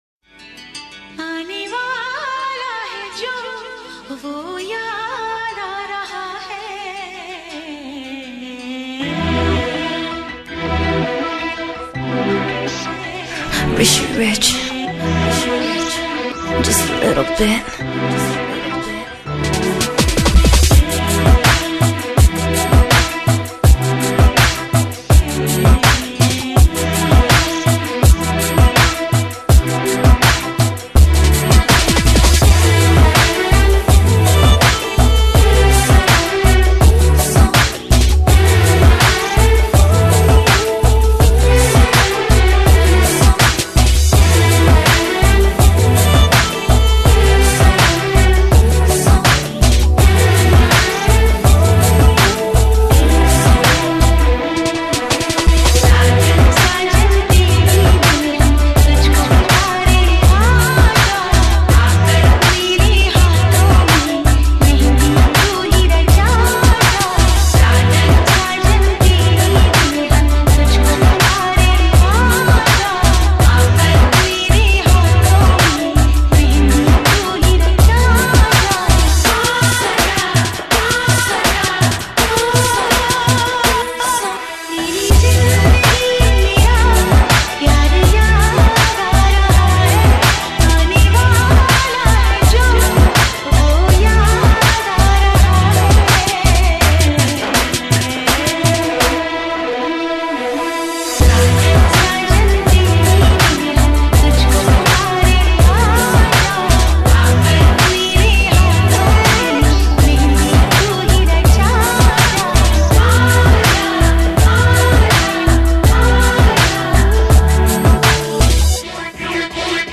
Hip Hop Remix